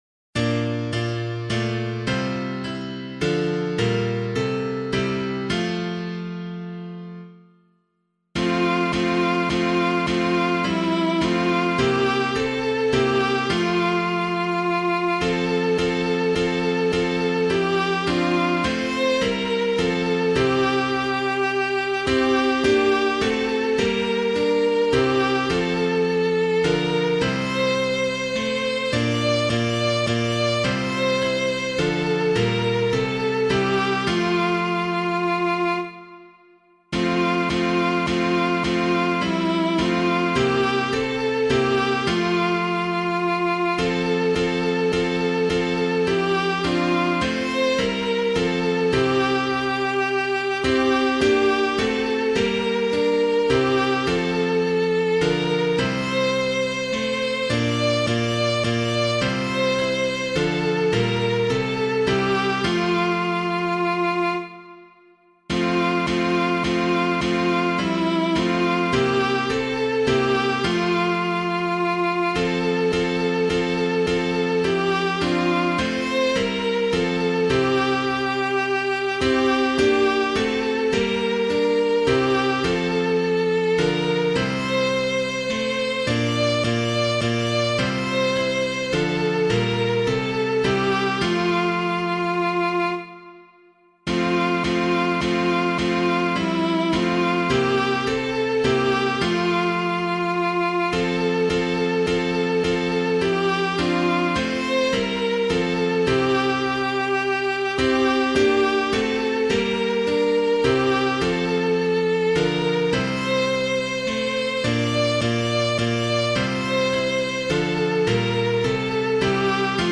piano
Let All on Earth Their Voices Raise [Mant - HURSLEY] - piano.mp3